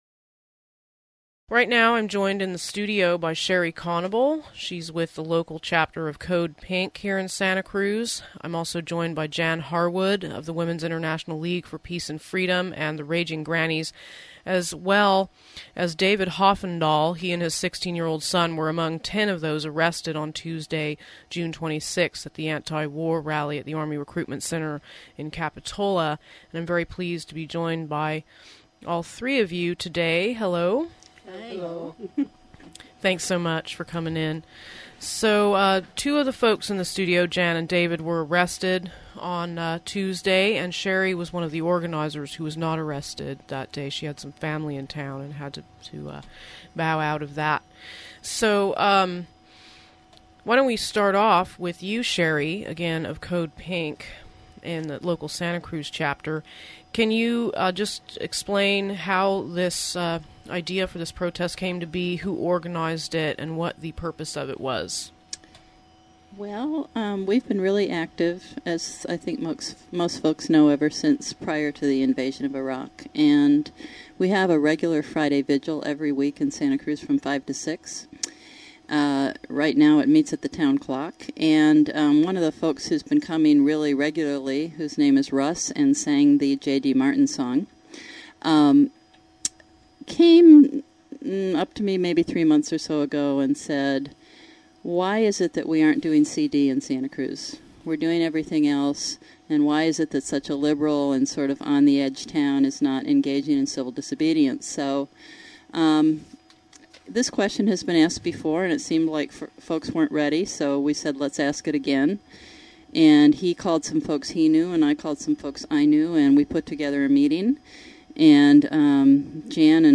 PeaceTalks: interview with two of those arrested and one organizer of 6_26_07 peace rally